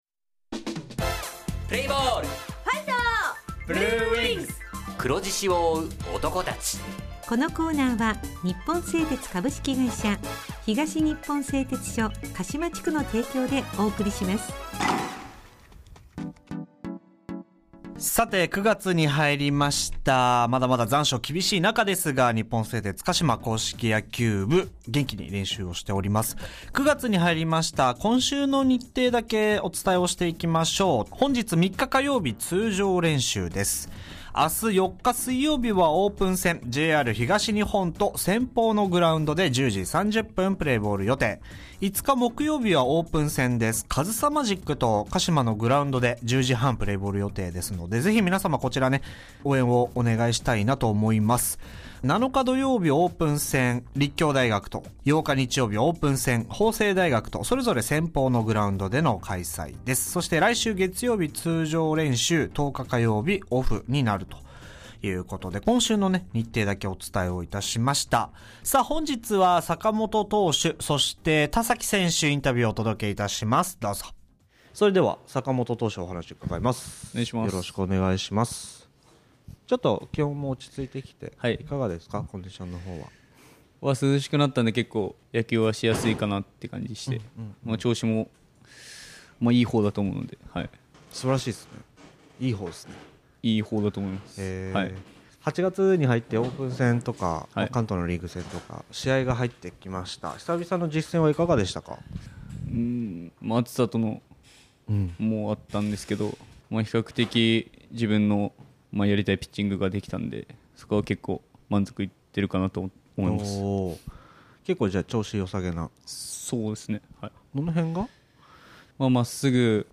地元ＦＭ放送局「エフエムかしま」にて鹿島硬式野球部の番組放送しています。
選手インタビュー 日本選手権にむけて / 今年チャレンジしてみたい事